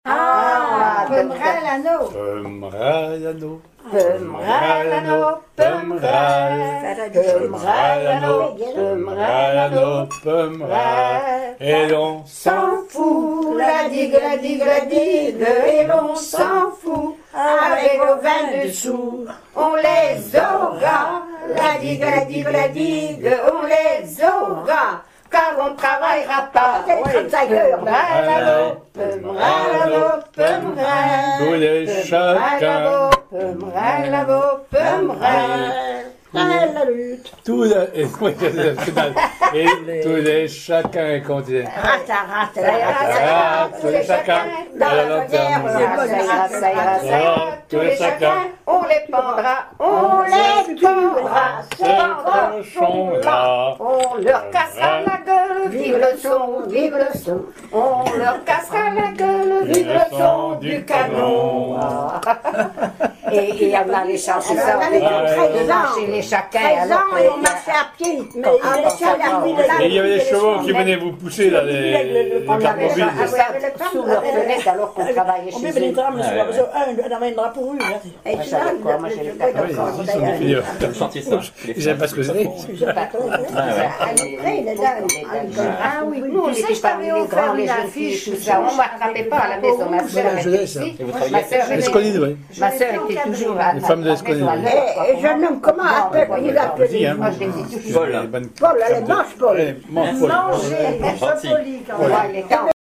Chansons populaires
Pièce musicale inédite